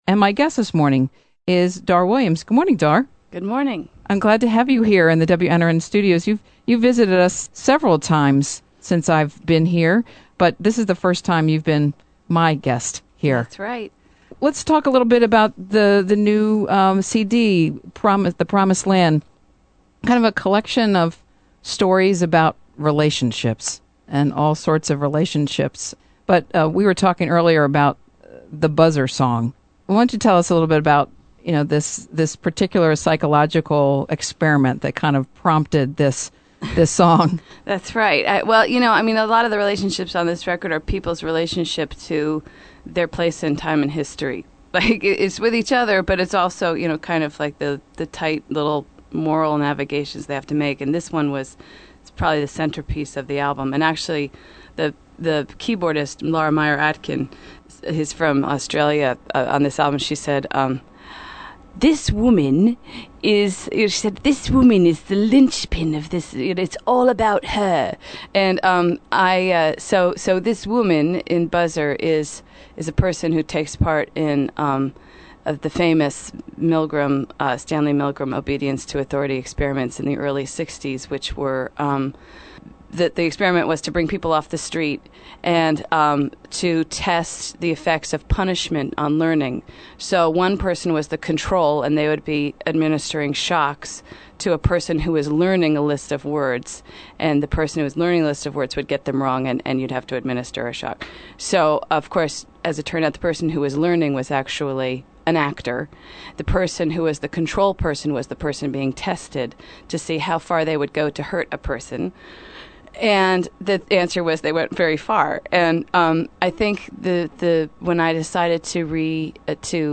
interview
played a couple of tunes